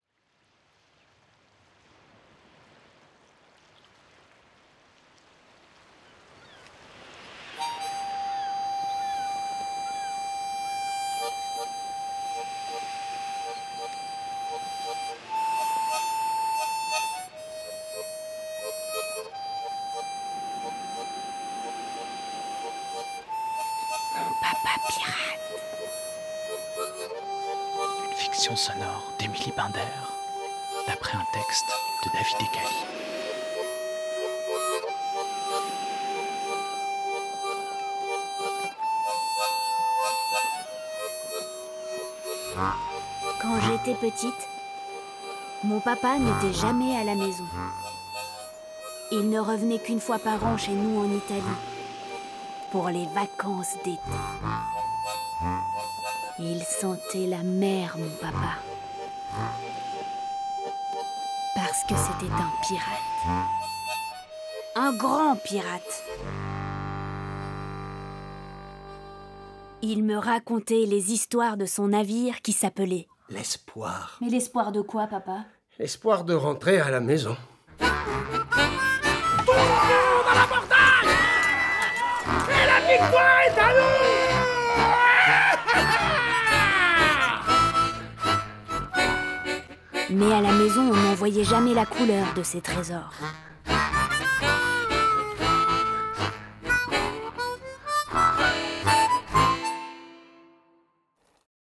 bande-annonce_papapirate.mp3